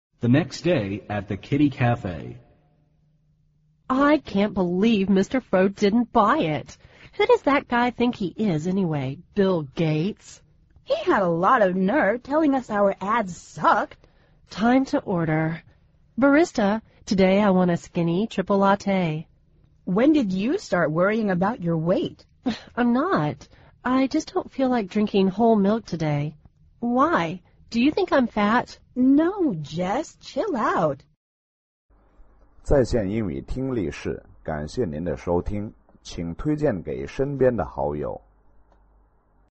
美语会话实录第166期(MP3+文本):Have a lot of nerve